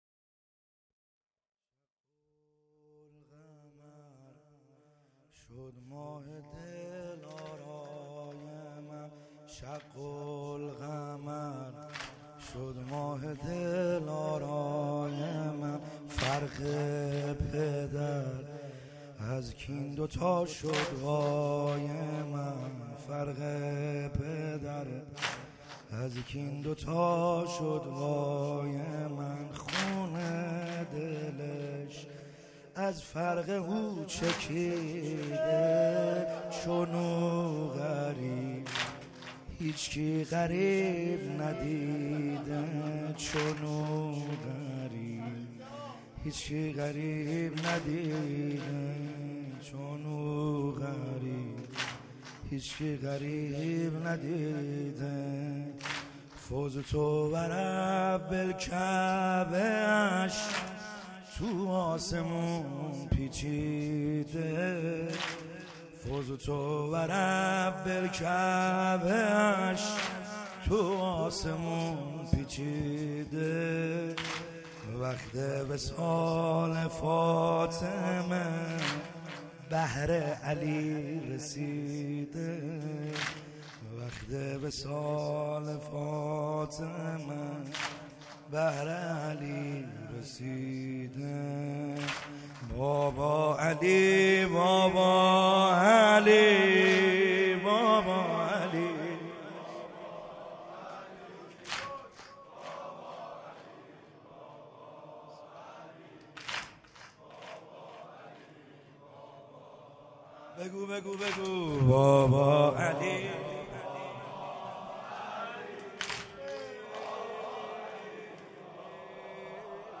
خیمه گاه - بیرق معظم محبین حضرت صاحب الزمان(عج) - سنگین | شق القمر شد ماه